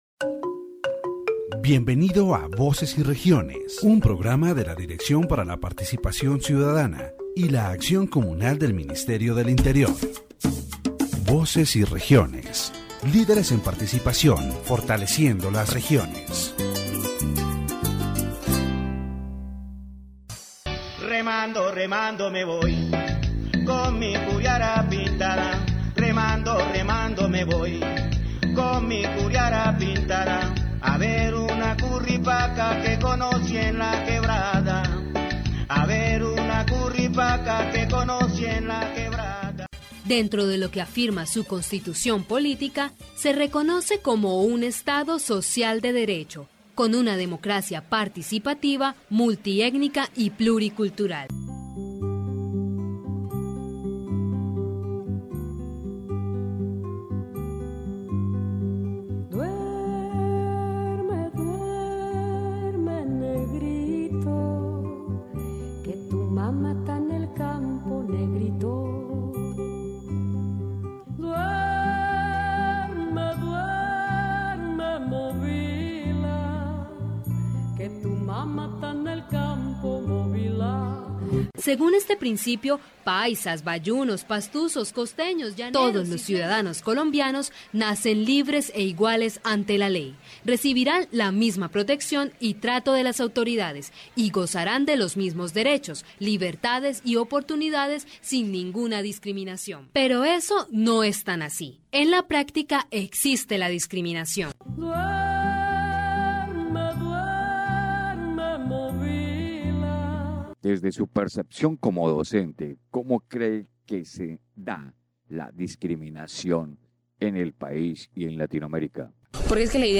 The episode features an interview with a teacher who shares his experience upon arriving in Istmina, Chocó, a region with a strong Afro-descendant identity.